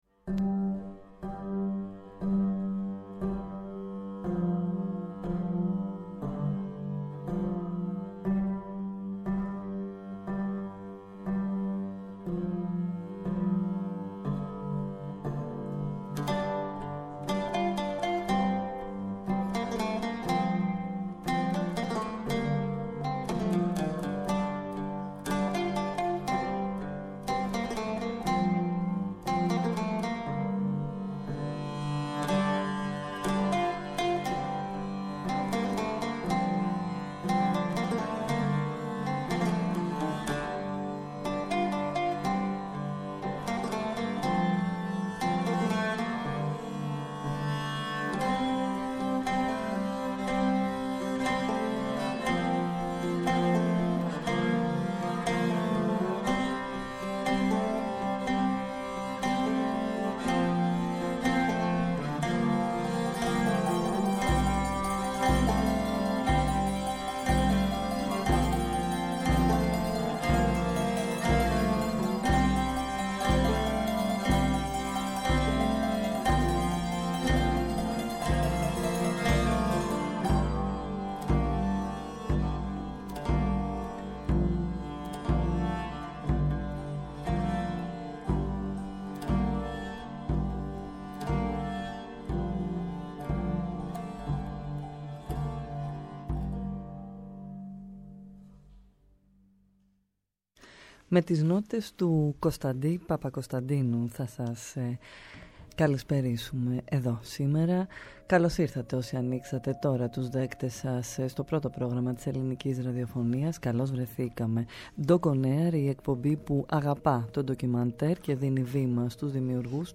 Απόψε στο Doc On Air, καλεσμένοι στο στούντιο του Α’ Προγράμματος της ΕΡΤ βρίσκονται οι σκηνοθέτες